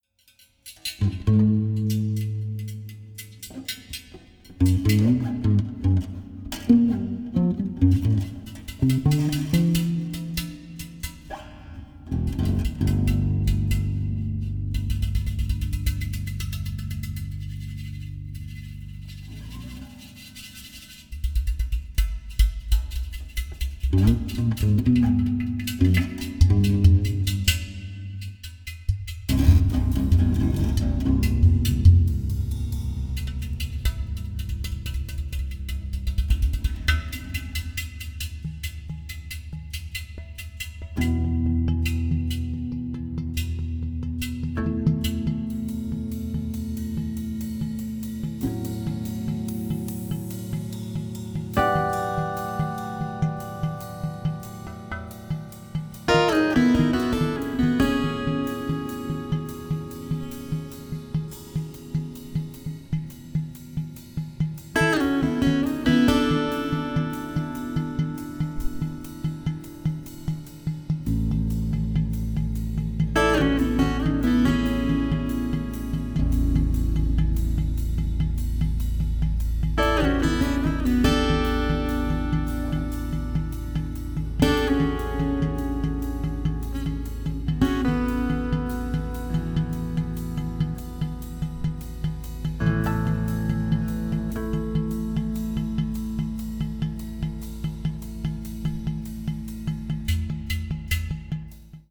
30-string Contra-Alto guitar
Percussion